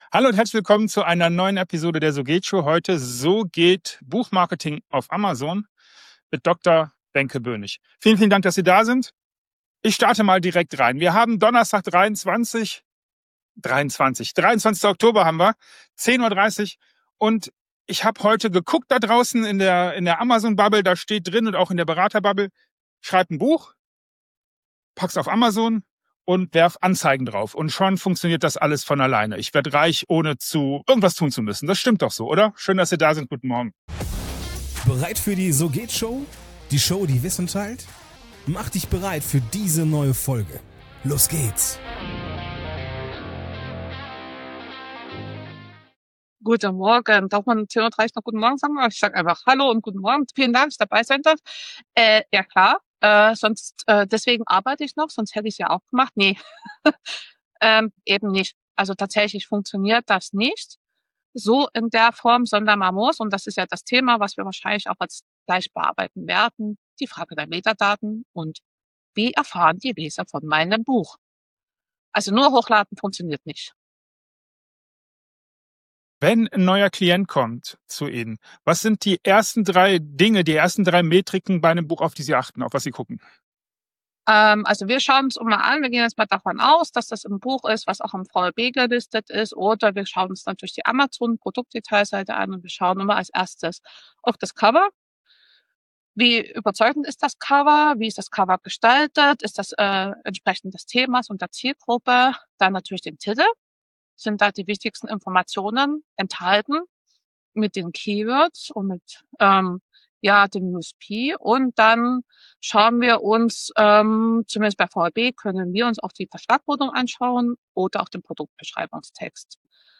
Mein Interviewgast hat 45 Sekunden für seine Antwort.
Keine Nachbearbeitung, keine zweiten Versuche – was gesagt wird, bleibt.